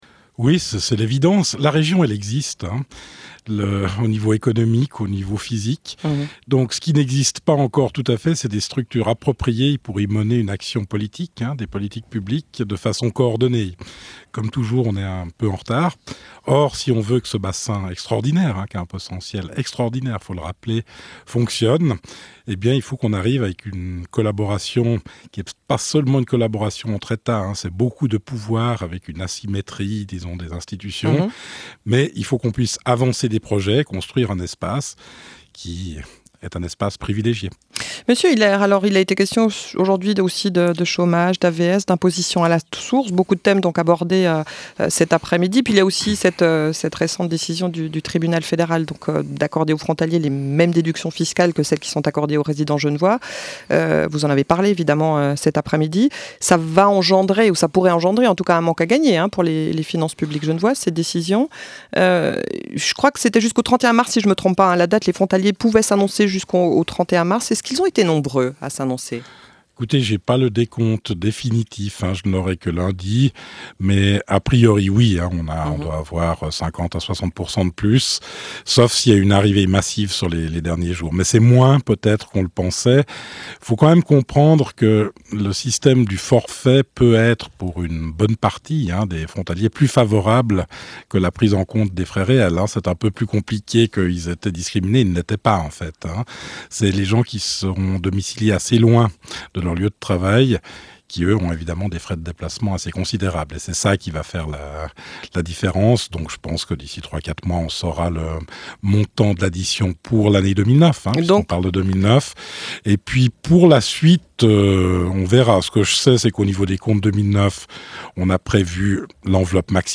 David Hiler, ministre des Finances du canton de Genève